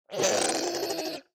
Minecraft Version Minecraft Version 25w18a Latest Release | Latest Snapshot 25w18a / assets / minecraft / sounds / mob / strider / retreat4.ogg Compare With Compare With Latest Release | Latest Snapshot
retreat4.ogg